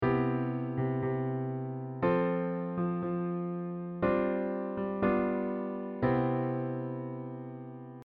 G7sus4-G7
G7sus4-G7.mp3